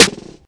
SGHZ_SNR.wav